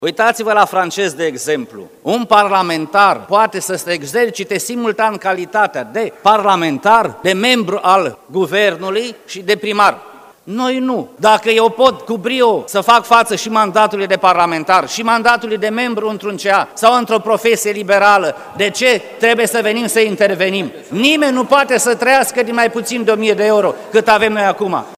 În timpul dezbaterilor, unii aleși s-au plâns că nu le ajunge salariul de la Parlament și ar vrea să fie lăsați să câștige bani și din alte activități.
Deputatul ALDE, Remus Borza s-a plâns că de când a devenit parlamentar și a renunțat la funcția de administrator judiciar câștigă de 200 de ori mai puțin.